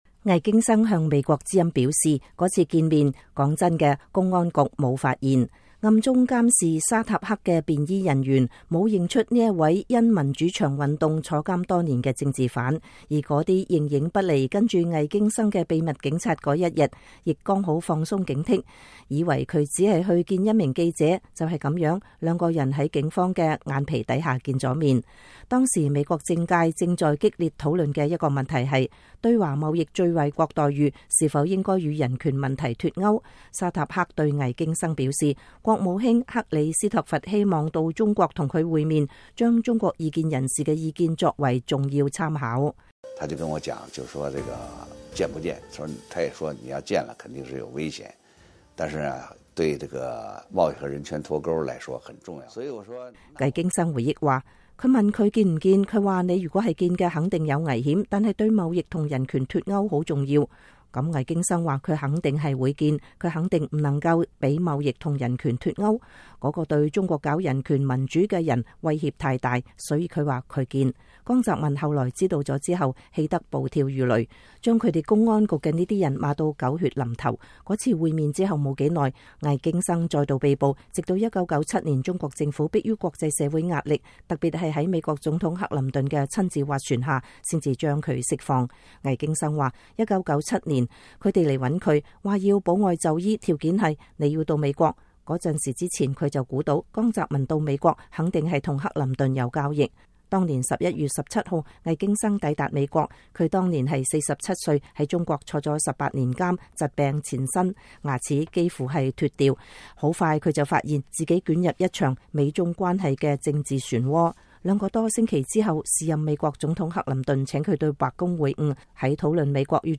魏京生專訪：美國讓共產黨掙了錢是巨大錯誤